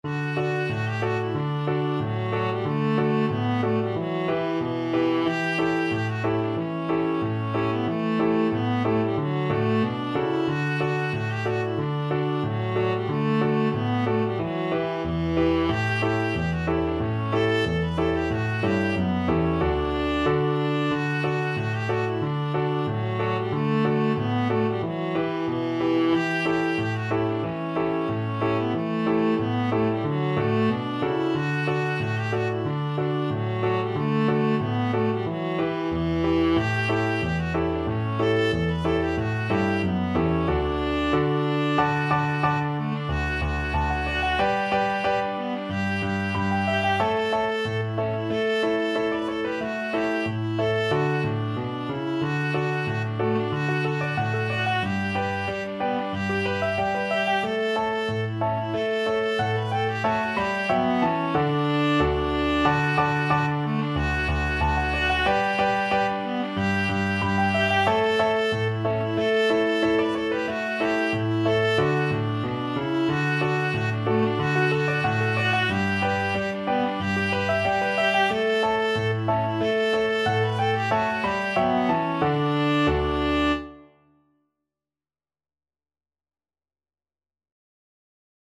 Viola
Traditional Music of unknown author.
D major (Sounding Pitch) (View more D major Music for Viola )
2/4 (View more 2/4 Music)
Moderato =c.92